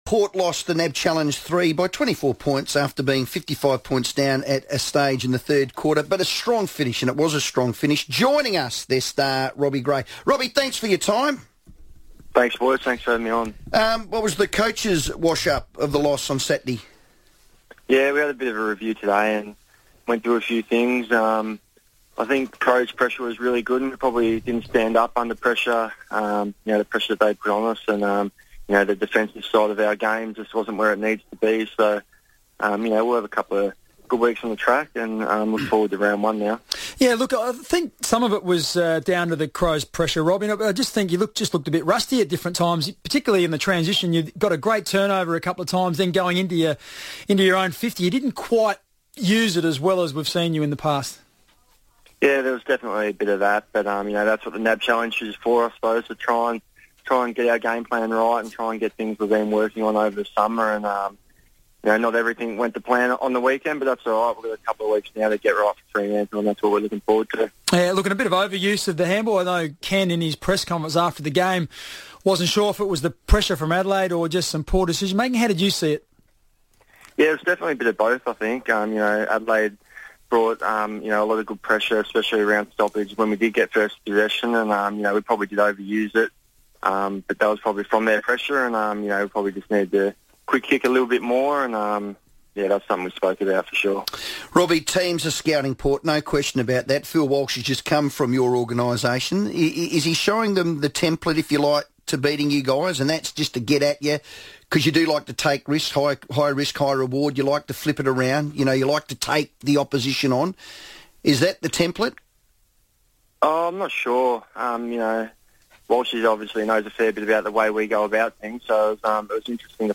Robbie Gray FIVEaa interview - Monday 23rd March, 2015